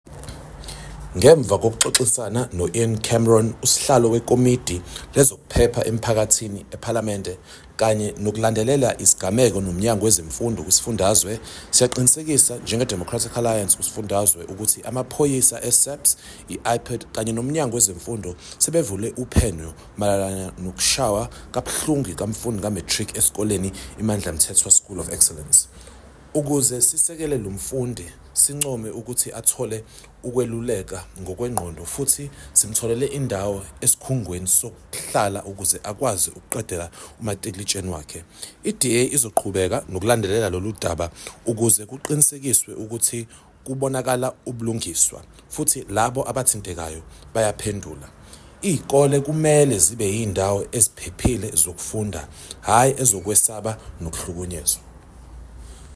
Issued by Sakhile Mngadi, MPL – DA KZN Spokesperson on Education
Note to Editors: Please note Sakhile Mngadi, MPL sound bite in